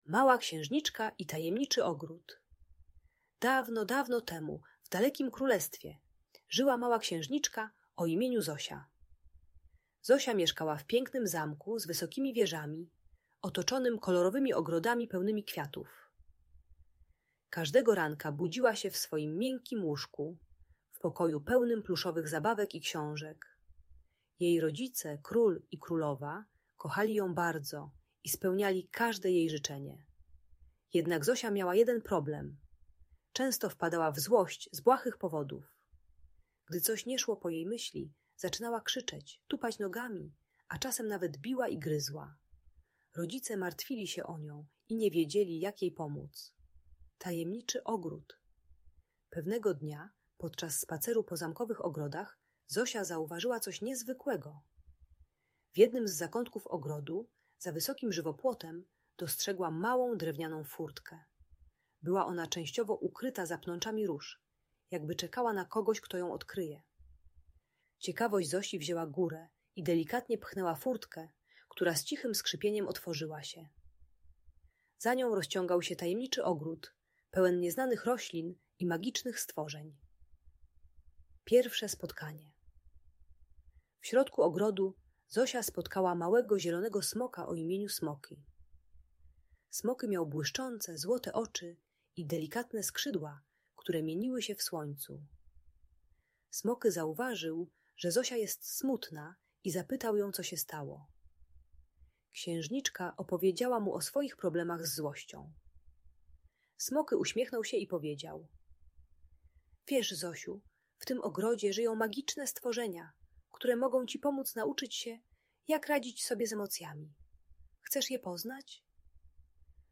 Historia Małej Księżniczki i Tajemniczego Ogrodu - Audiobajka